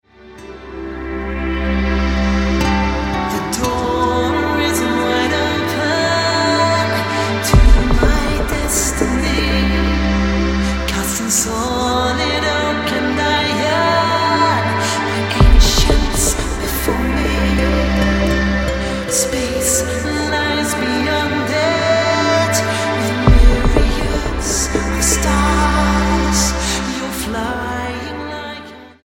STYLE: Pop
Tremendous vocals and playing throughout